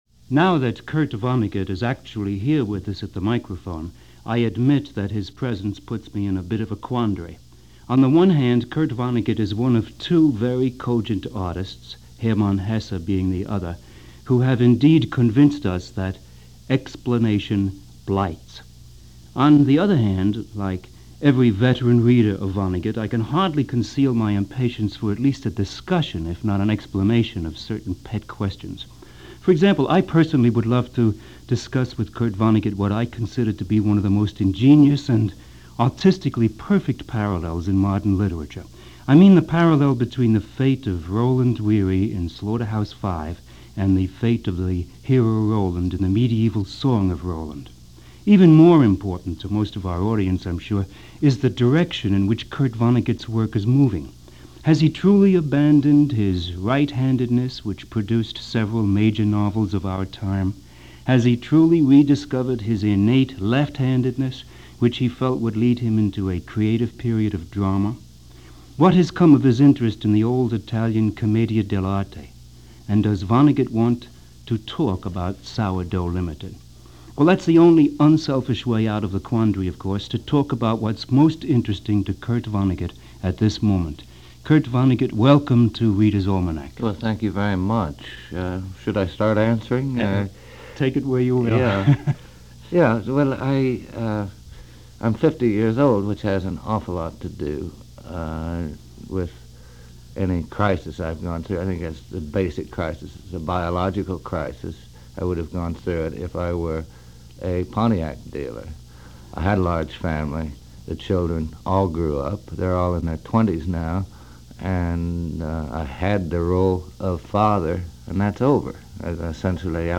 – Readers Almanac – A Talk With Kurt Vonnegut – January 28, 1973 – Gordon Skene Sound Collection –